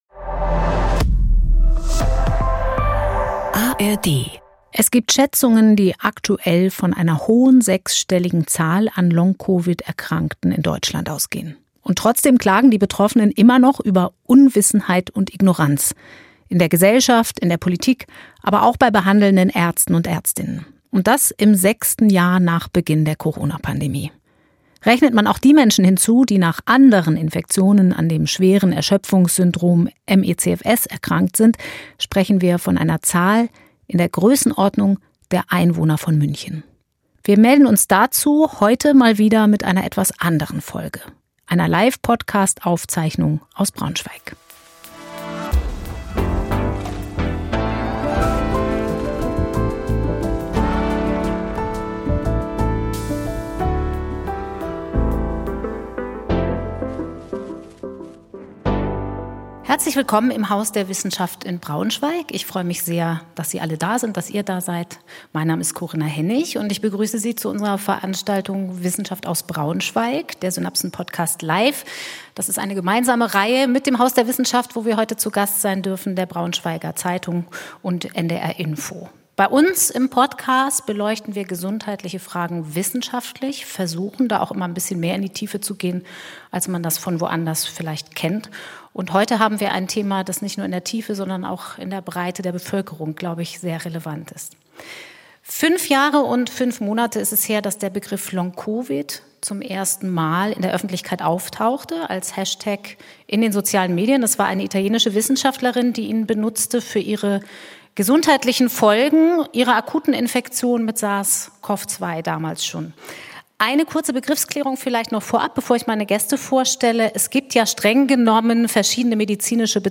in einer Live-Podcastfolge aus dem Haus der Wissenschaft in Braunschweig mit Experten.